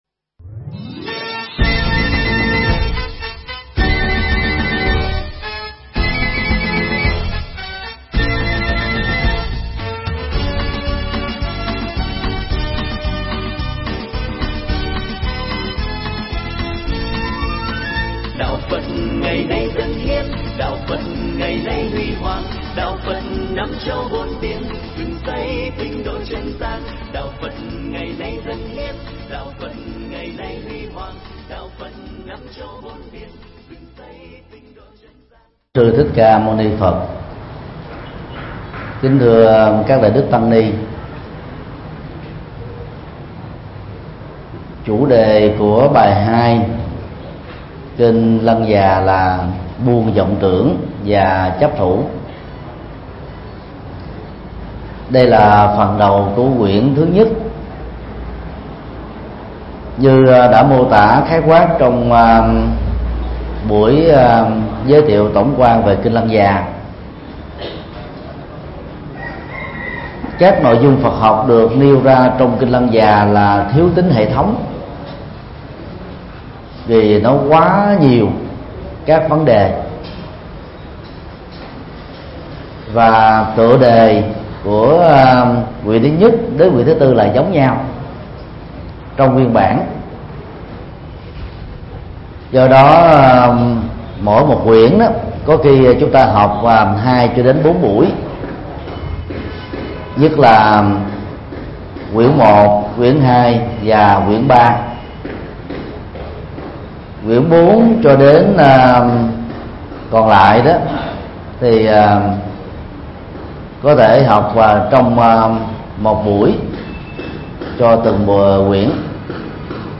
Mp3 Pháp Thoại Kinh Lăng Già 02: Buông Vọng Tưởng Và Chấp Thủ – Thầy Thích Nhật Từ Giảng tại Học viện Phật giáo Việt nam tại TP. HCM, ngày 15 tháng 9 năm 2014